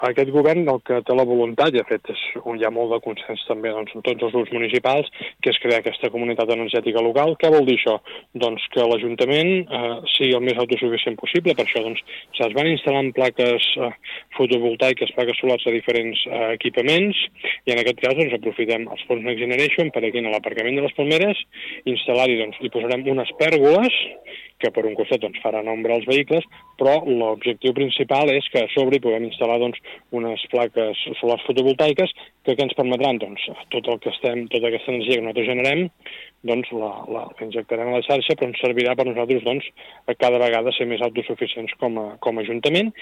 Segons ha explicat l’alcalde Marc Buch a RCT, aquesta actuació s’emmarca dins la voluntat del consistori de crear una comunitat energètica local que permeti avançar cap a l’autosuficiència energètica dels equipaments municipals.